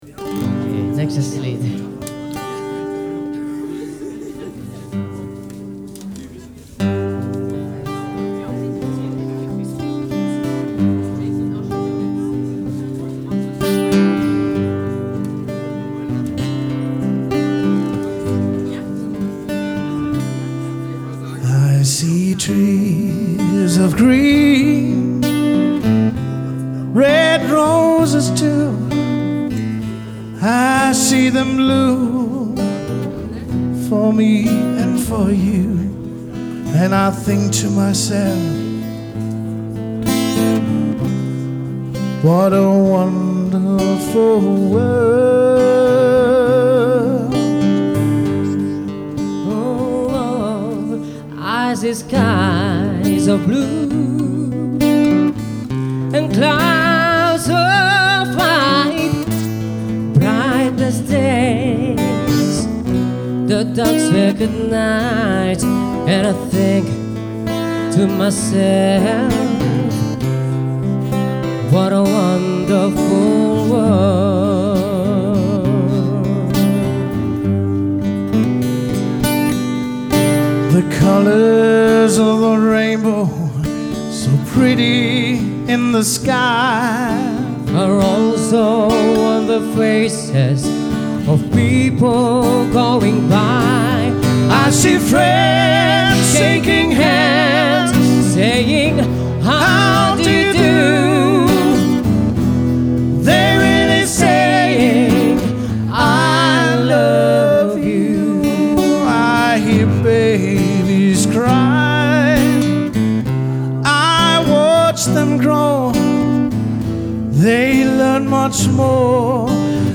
- Solosänger oder als Duo / Trio